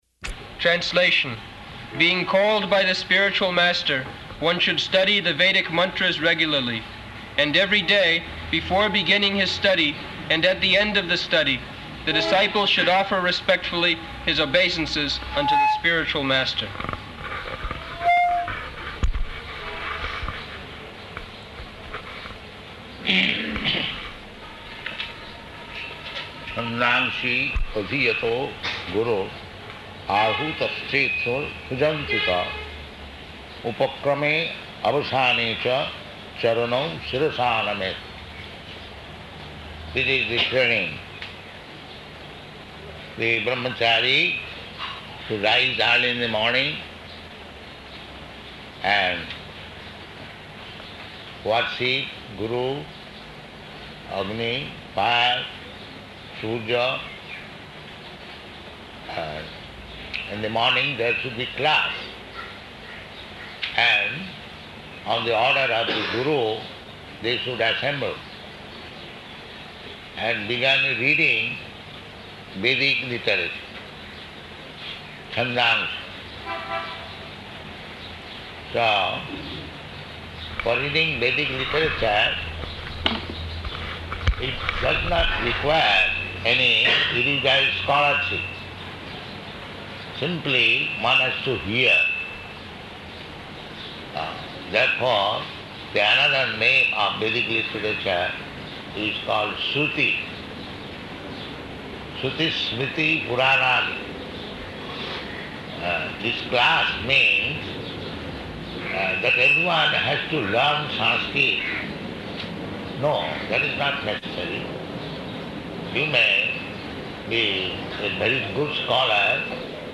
Location: Bombay